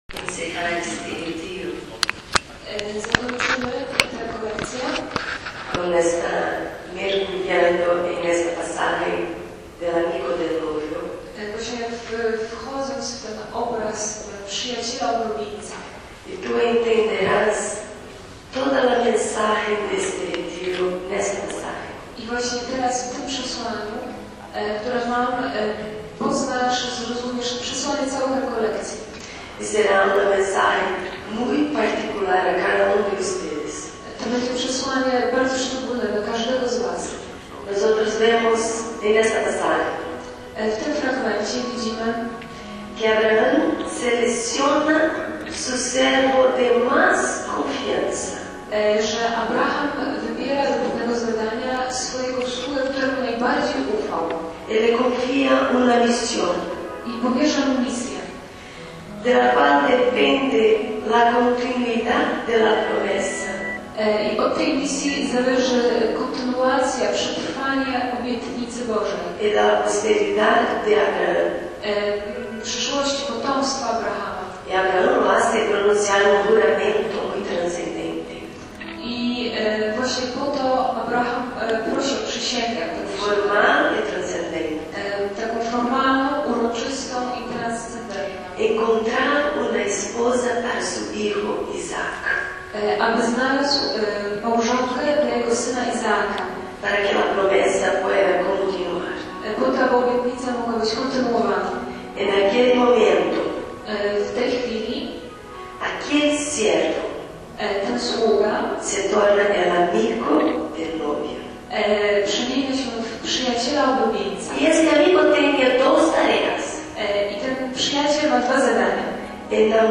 by upublicznić nagranie konferencji z rekolekcji kapłańskich